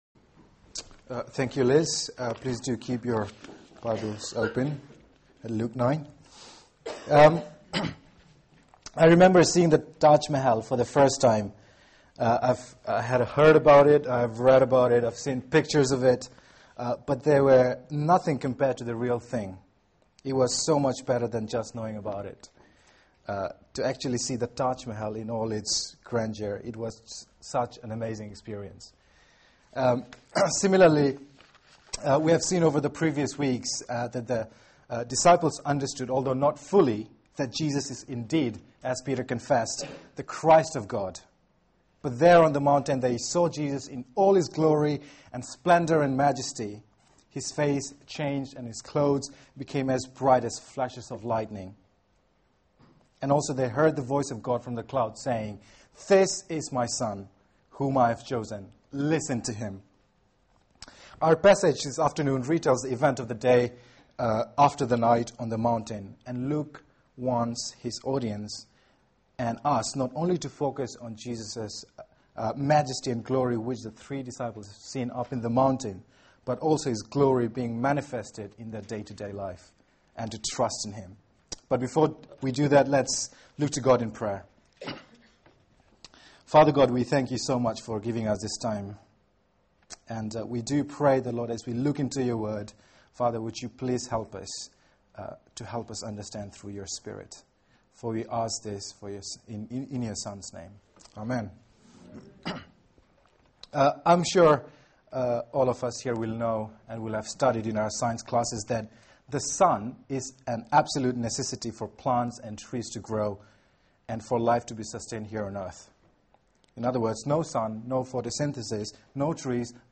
Media for 4pm Service on Sun 14th Oct 2012 16:00 Speaker